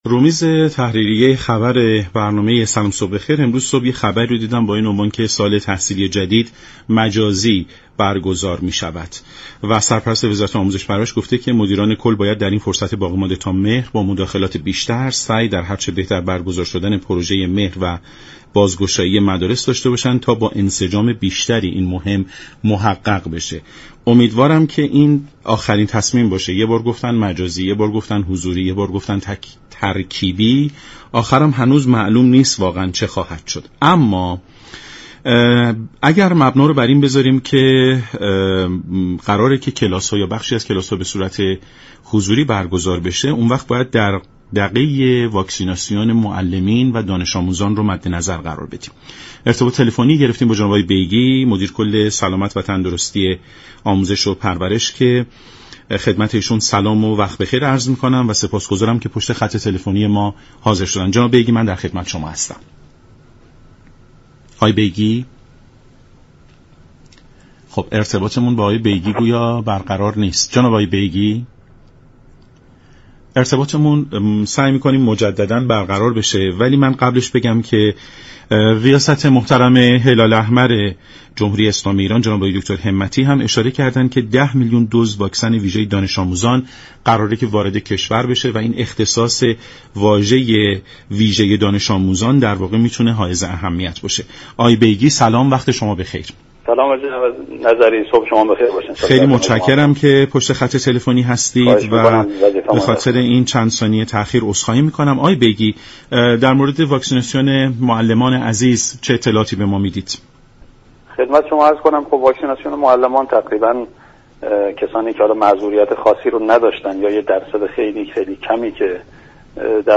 مدیر كل دفتر سلامت و تندرستی وزارت آموزش و پرورش گفت: تاكنون مجوز استفاده از واكسن موجود در كشور، برای گروه سنی زیر 18 سال صادر نشده است.